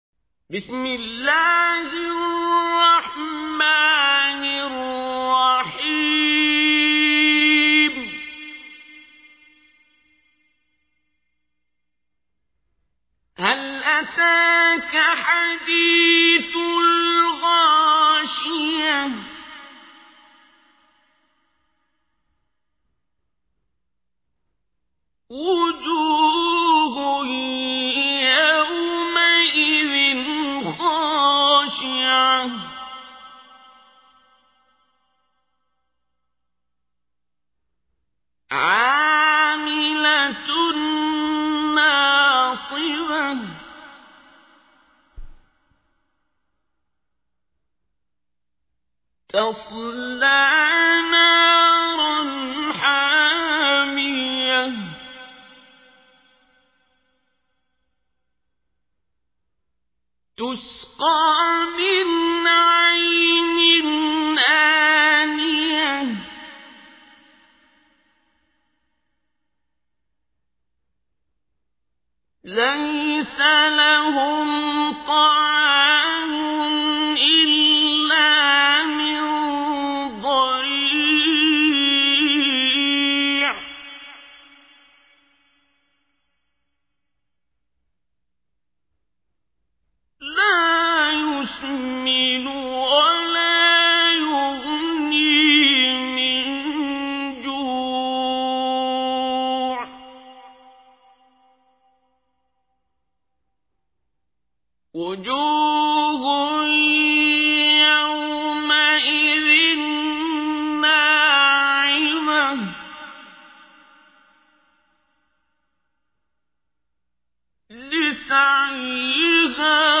تحميل : 88. سورة الغاشية / القارئ عبد الباسط عبد الصمد / القرآن الكريم / موقع يا حسين